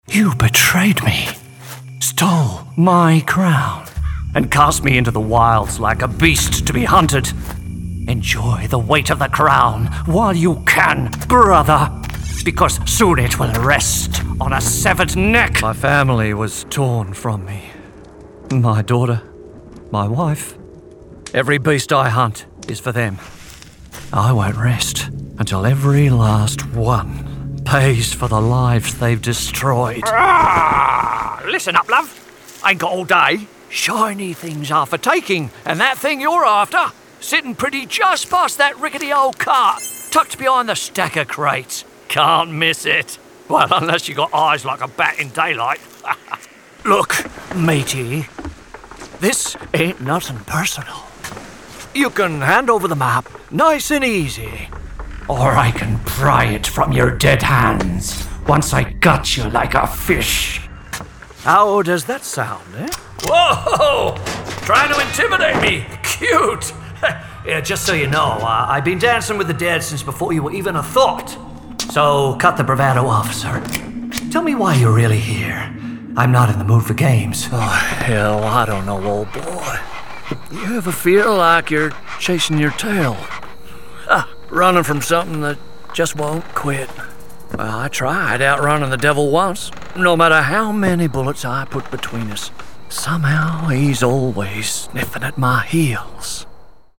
Character reel
American, Australian, Cockney, Irish, RP ('Received Pronunciation'), Southern American, Various
Character, Versatile, Acting, Confident, Games